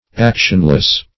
Actionless \Ac"tion*less\, a. Void of action.